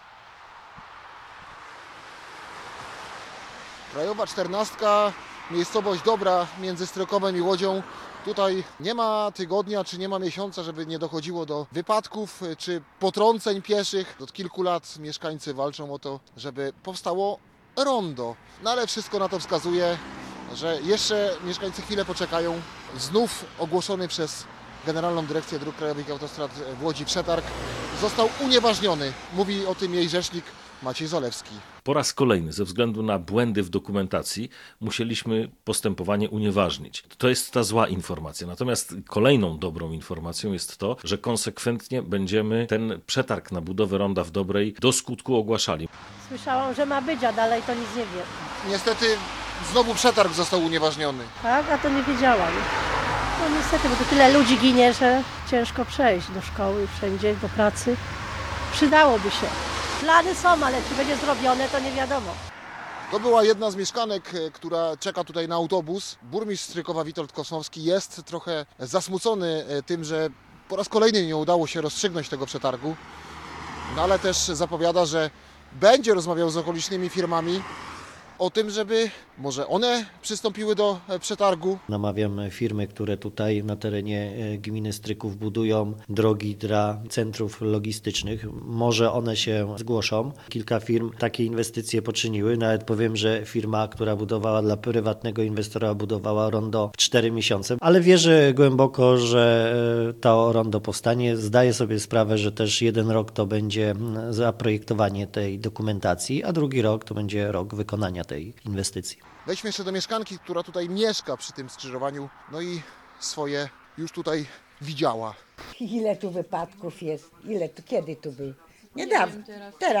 Jedna z mieszkanek opowiadała naszemu reporterowi, że kilka dni temu w Dobrej doszło do kolejnego wypadku .